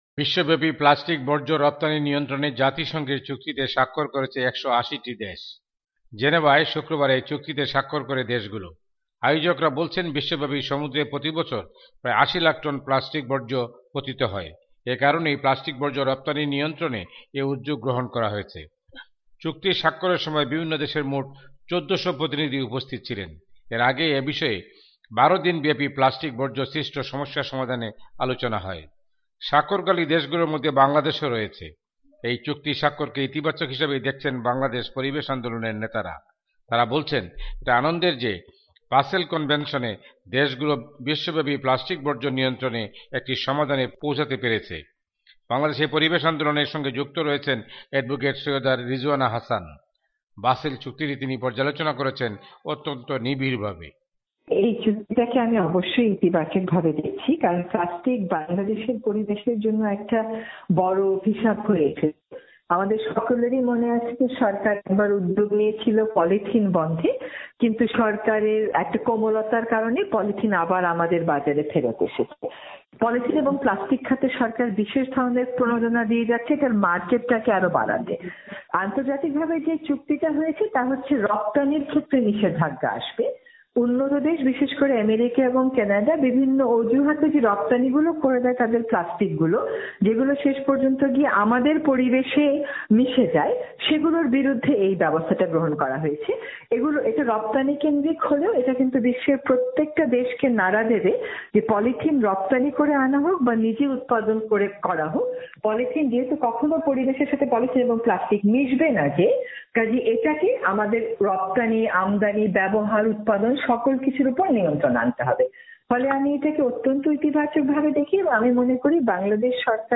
রিপোর্ট।